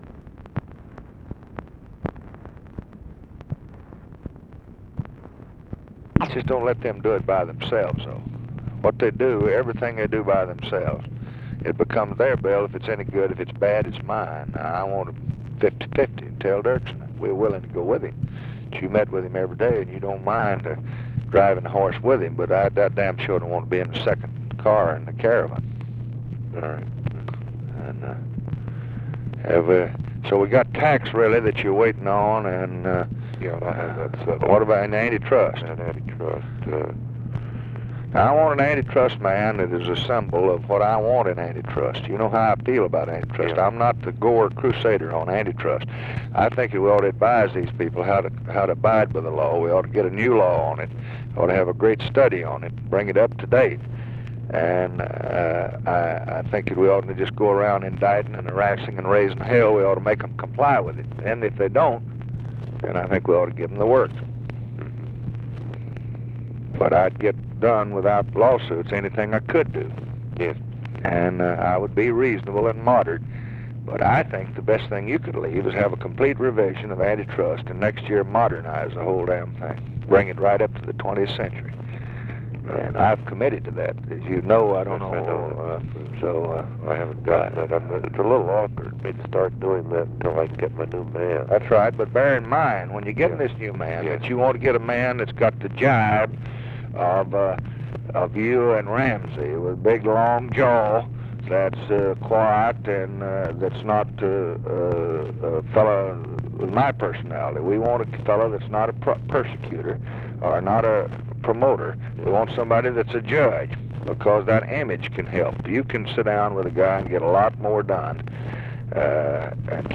Conversation with NICHOLAS KATZENBACH, April 7, 1965
Secret White House Tapes